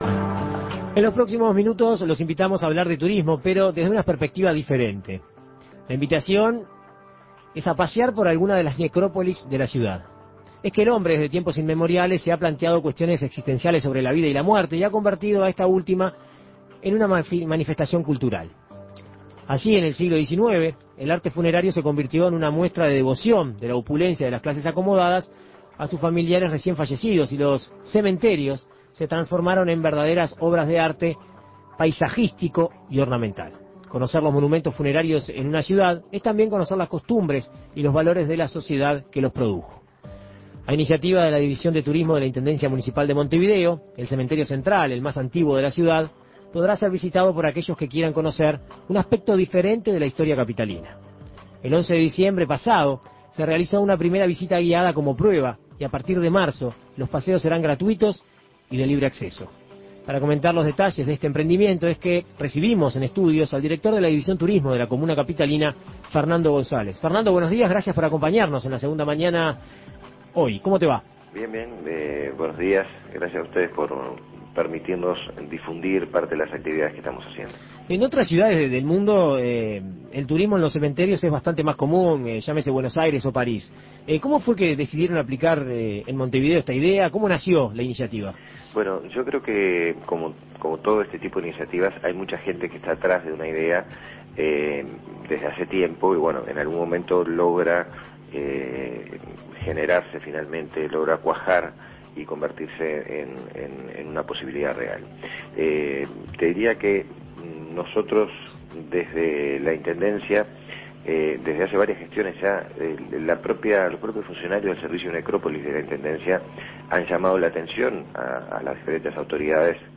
A partir de una iniciativa de la División de Turismo de la Intendencia Municipal de Montevideo (IMM), el Cementerio Central, el más antiguo de la ciudad, podrá ser visitado como paseo turístico por aquellos que deseen conocer la capital desde otro punto de vista. Fernando González, director de Turismo de la IMM, brindó a El Espectador más detalles sobre el emprendimiento.